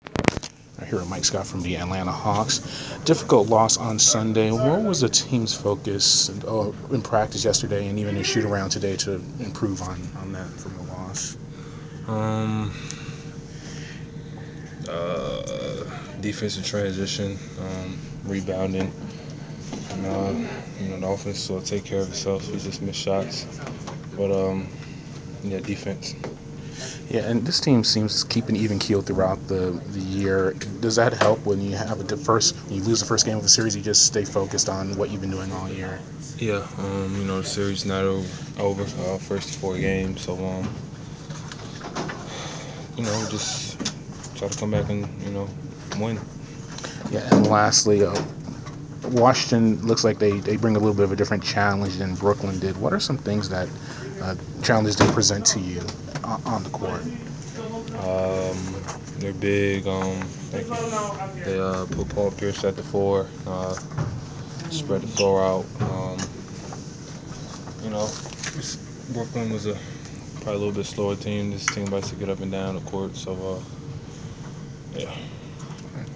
Inside the Inquirer: Pregame interview with Atlanta Hawks Mike Scott 5/5/15